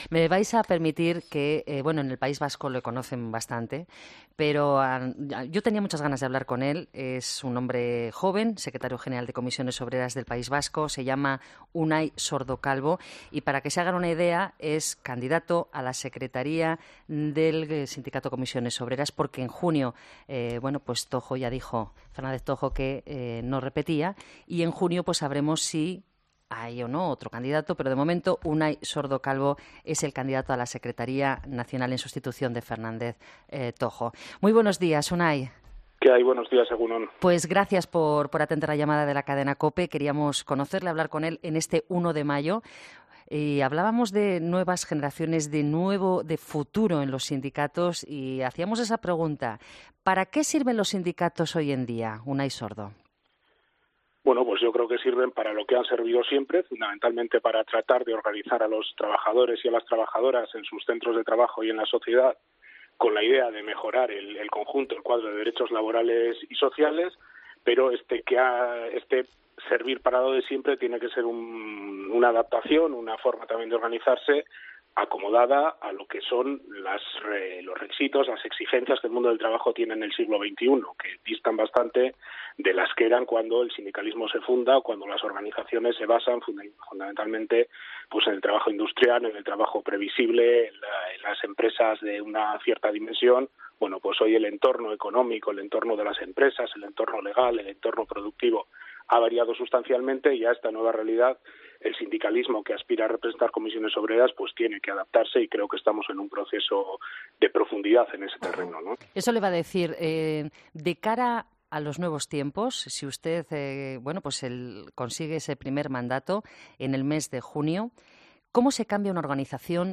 Escucha la entrevista a Unai Sordo, candidato a la secretaría general de CCOO, en 'Herrera en COPE'